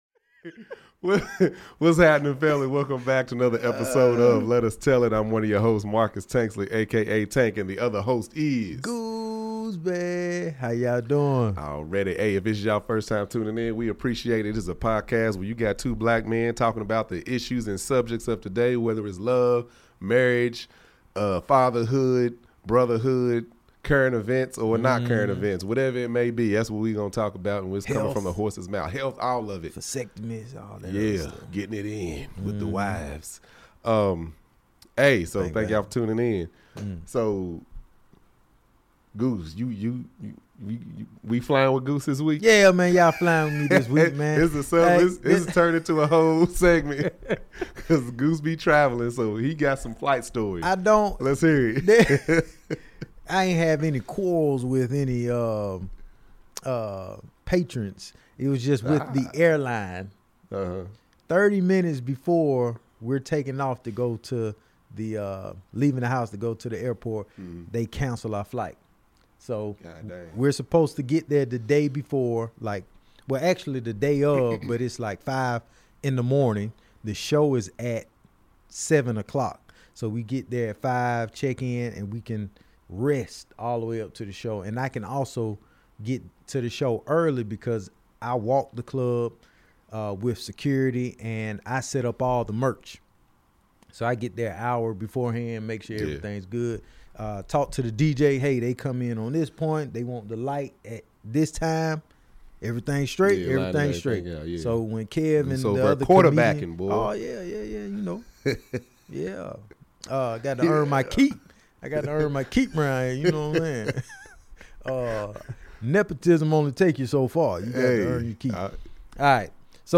This episode we discuss a $550K date night and more Youtube rabbit hole videos. Plus we have a few technical difficulties.